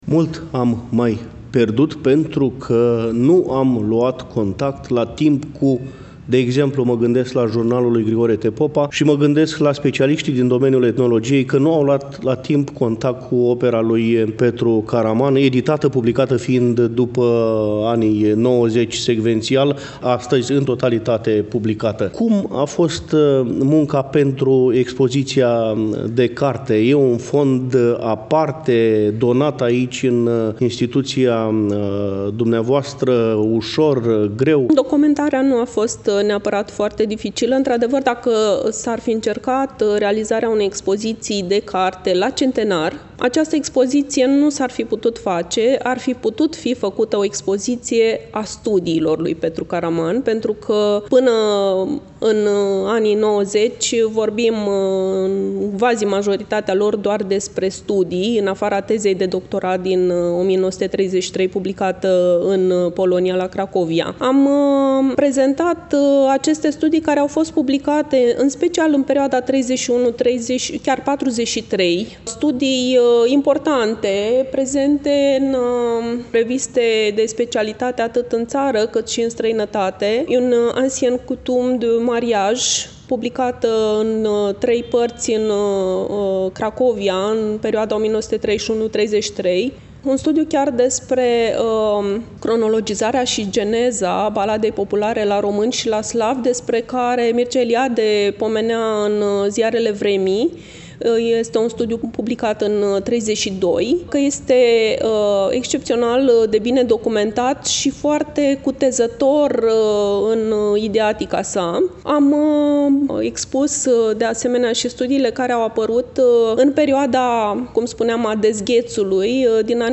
Cu prilejul împlinirii, la 14 decembrie 2023, a 125 de ani de la nașterea cel mai mare etnolog român, la Iași, în Sala „Hasdeu” din incinta BCU „Mihai Eminescu”, a fost organizat un eveniment de înaltă ținută academică.